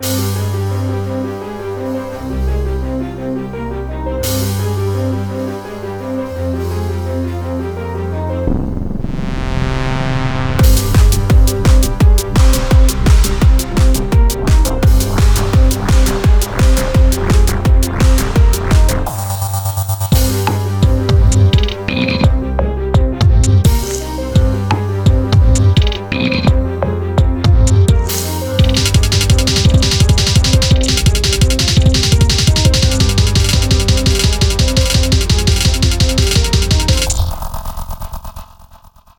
• テンポは高速（約150〜160BPM）。まさに「走っている」テンポ感。
• 音の密度が高めでスネアや効果音的なSEがテンポよく詰め込まれている。
• ストリングスや電子音、金属的な音が混在し混乱感を視覚的に表現
• 短い反復フレーズとアクセントの強い打楽器で、緊張の「圧」を演出。
【ループ対応ogg】
フリーBGM ゲームBGM パニック 緊張感 逃走 アクション